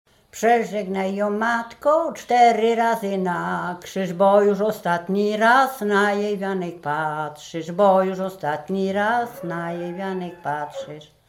Łęczyckie
województwo łódzkie, powiat poddębicki, gmina Wartkowice, wieś Sucha Dolna
Weselna
weselne błogosławieństwo